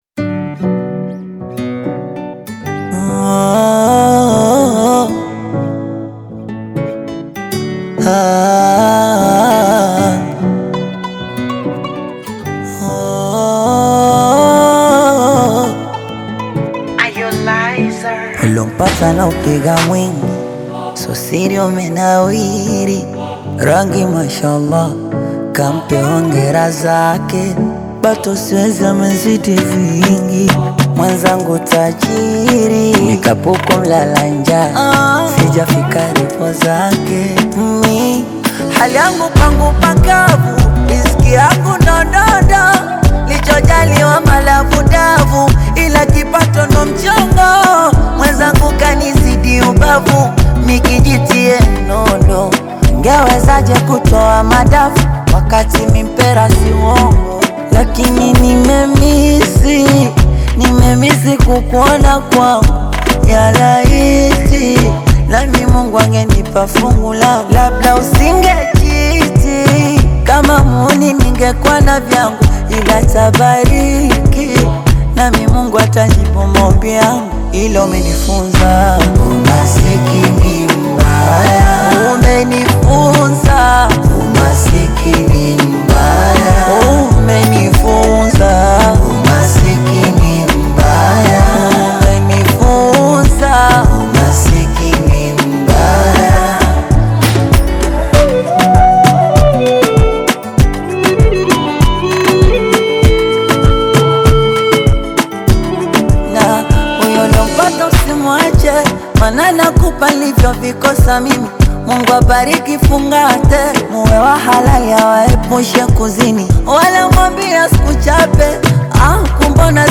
Singeli, Bongo Fleva, Amapiano, Afro Pop and Zouk